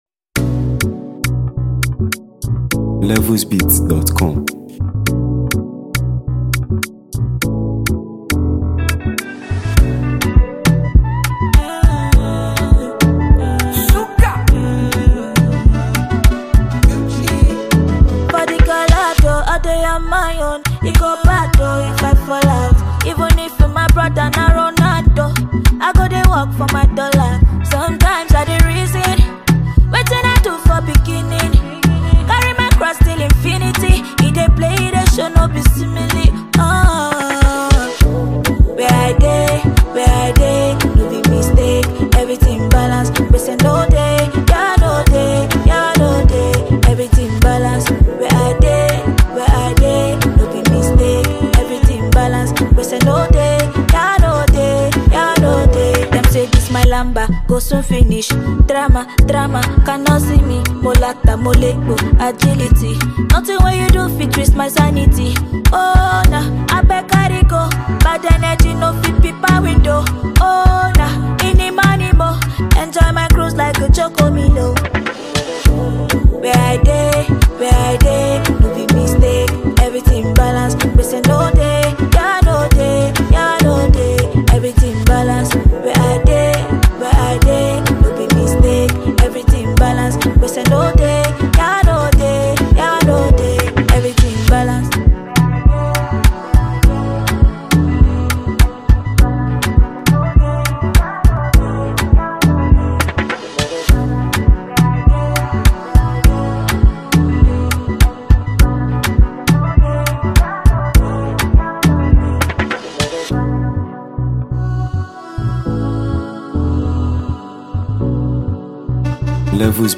exceptional and vibrant vocals
ballad